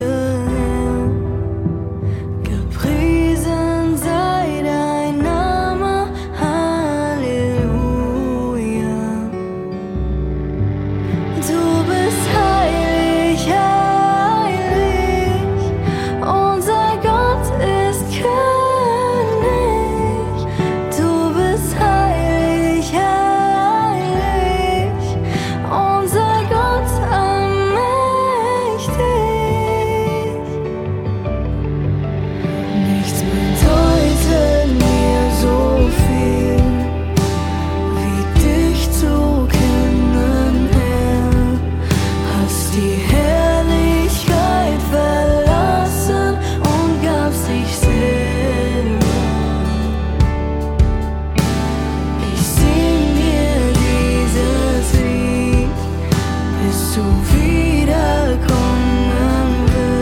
Lobpreis
Gesang